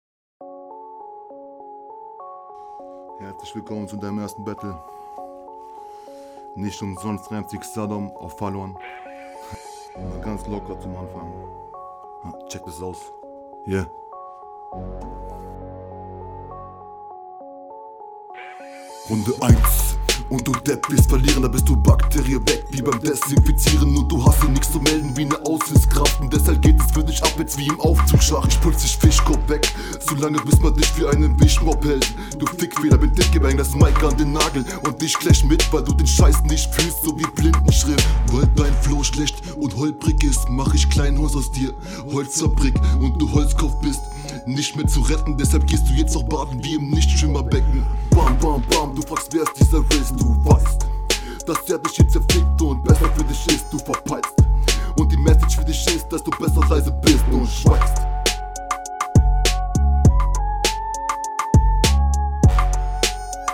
Flow: Sehr stabil, harmonierst sehr gut mit dem Beat gerade sowohl flow als auch stimmlich, …
Wow, bin positiv überrascht, du hast einen sehr stabilen Flow und auch eine angenehme Rapstimme.